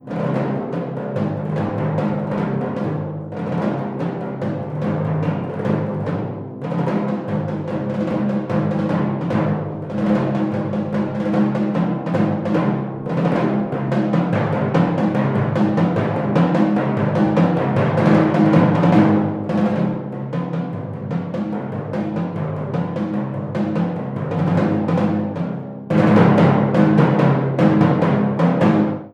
Written for three choirs of strings (violin, viola, cello) and continuo, the Brandenburg Concerto No. 3 explores in depth the surprising possibilities inherent in groups of similar-sounding instruments. One of the critical ingredients is uniform virtuosity; the nine string parts are essentially equal, and each player comes forward at least briefly as a soloist. Subtle variations in texture and tone color are also extremely important.